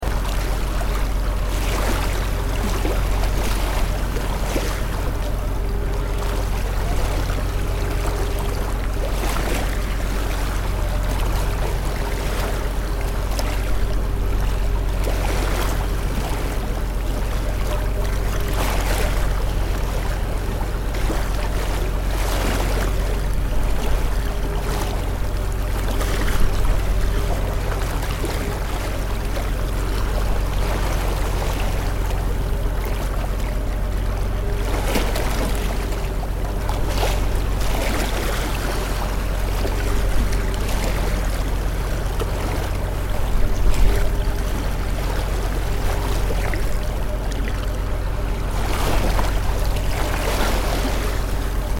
جلوه های صوتی
دانلود صدای دریا 4 از ساعد نیوز با لینک مستقیم و کیفیت بالا